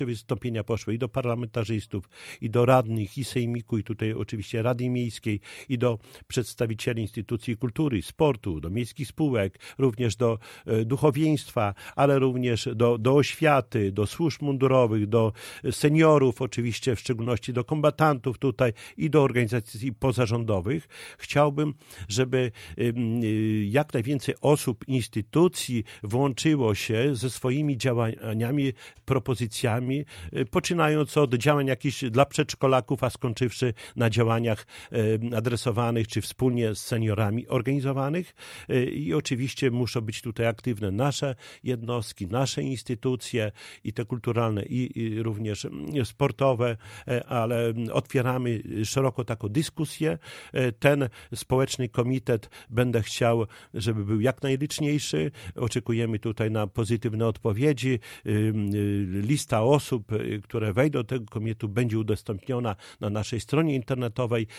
Szczegóły przedstawił w piątek (05.01) gość Radia 5 Czesław Renkiewicz, prezydent miasta.